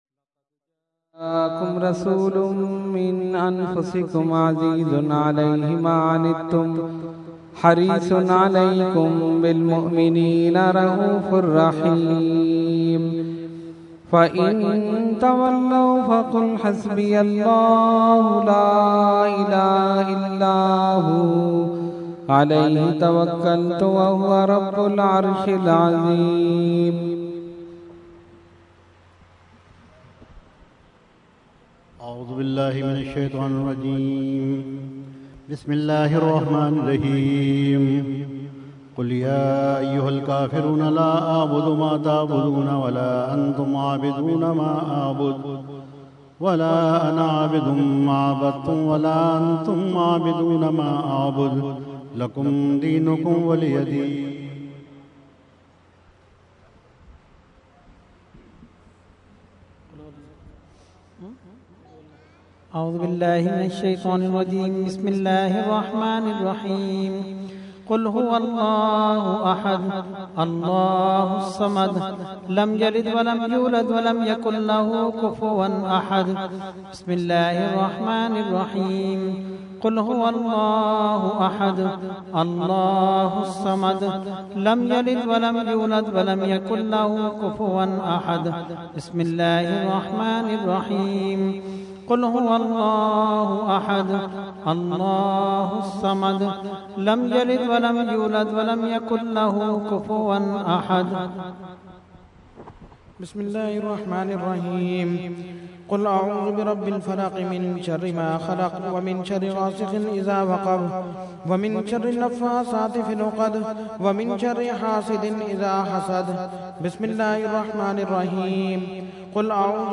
01-Starting Fatiha and Shajrah Shareef.mp3